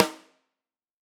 Index of /musicradar/Snares/Tama Wood